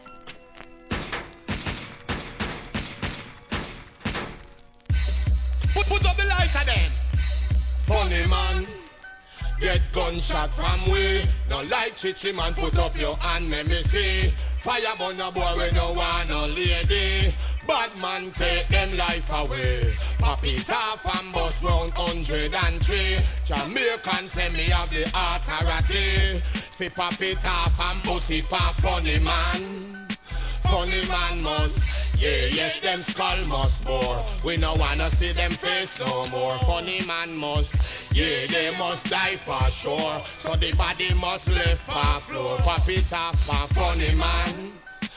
REGGAE
途中DANCEHALL RHTHMへの展開もあり!